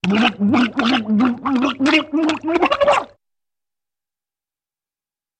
На этой странице собраны мрачные и атмосферные звуки, связанные с трупами: от приглушенных стонов до леденящих душу шорохов.